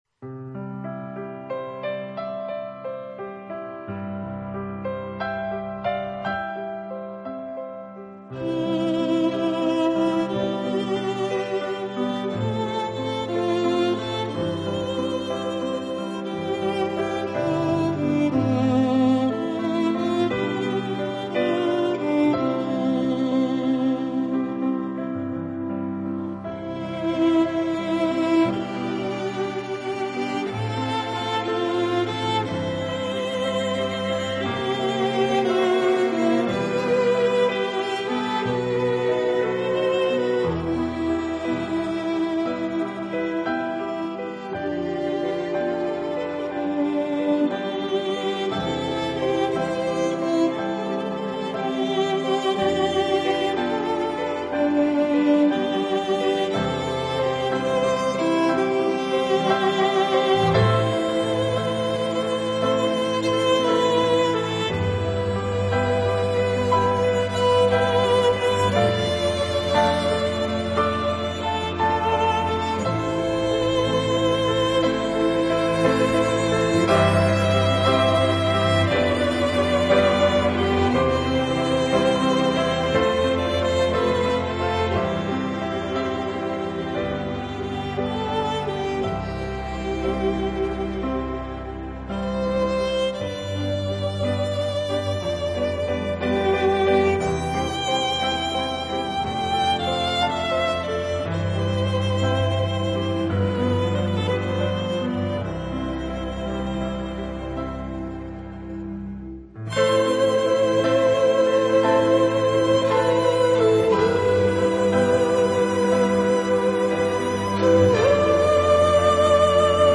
音乐类型：Classical